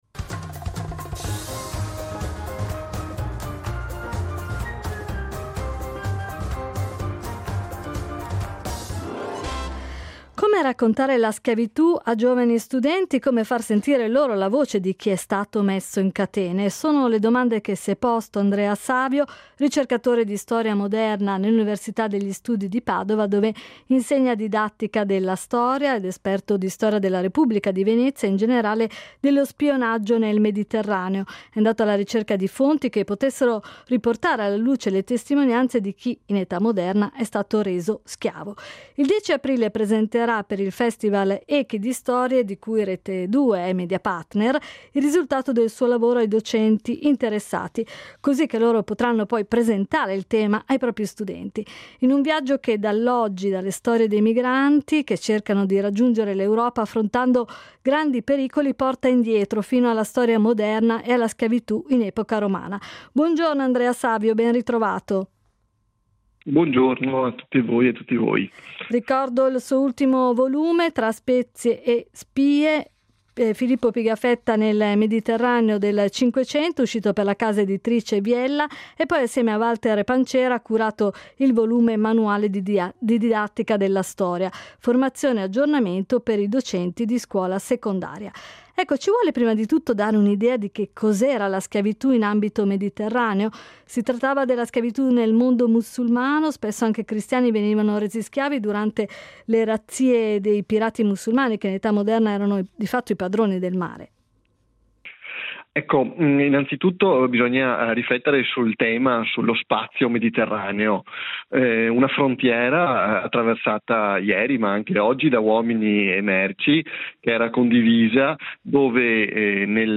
Con il ricercatore di Storia moderna